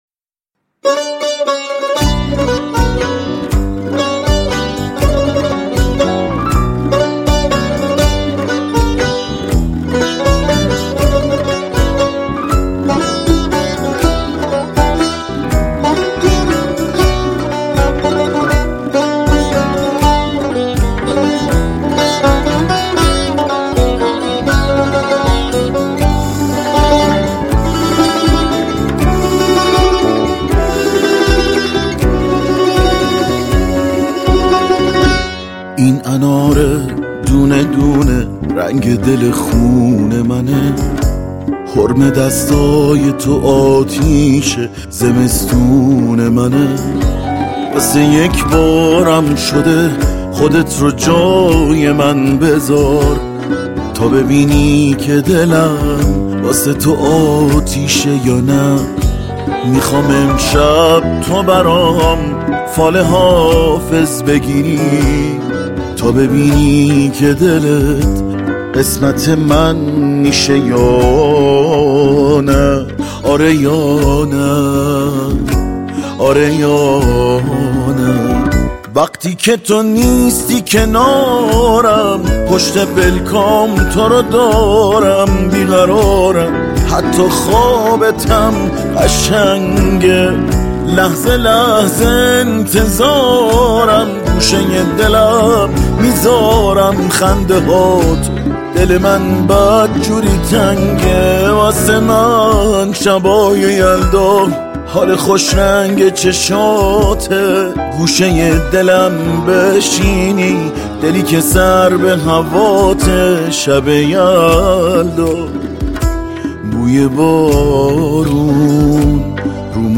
آهنگهای پاپ فارسی
بی کلام